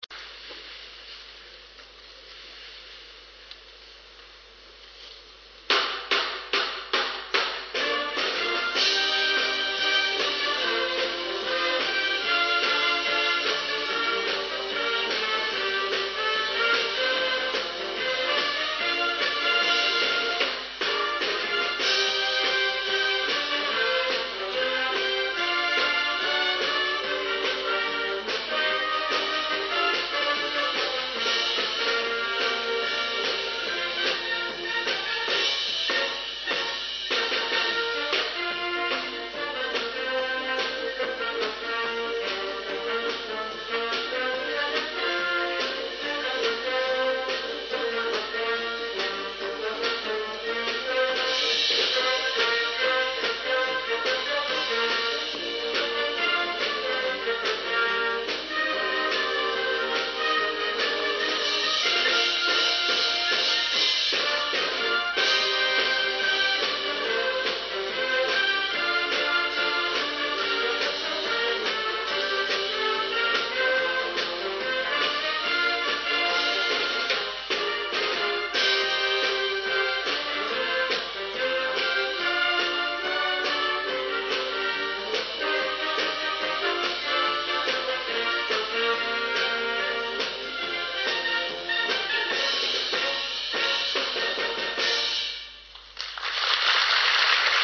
合奏を録音したMP3ファイルです。
こちらは、2000年の富岡六旗での演奏です。演奏はこの年の当番校、明治大学です。･･･ってか、パーカッションの譜面、違うよ（￣ロ￣；）！！！
でも全体的に、演奏は上手い。